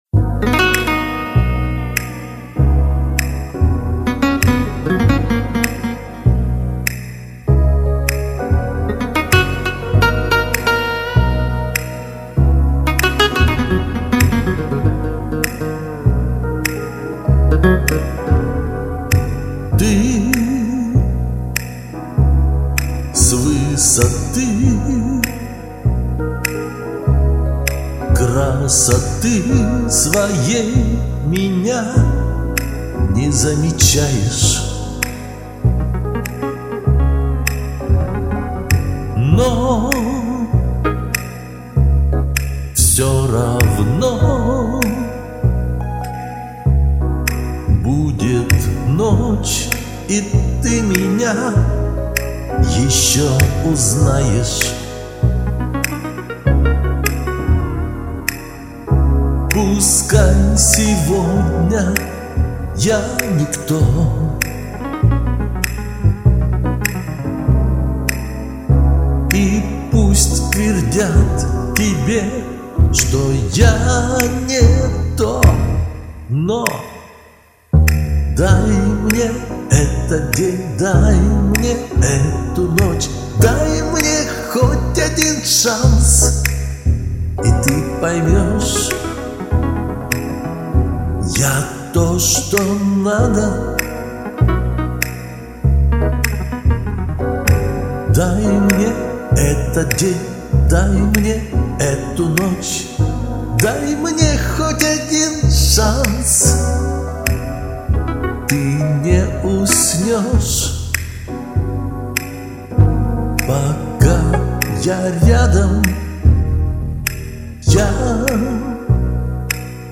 есть напор эмоции, но жестко